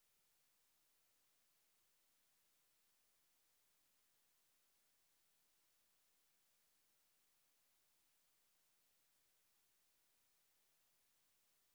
Марш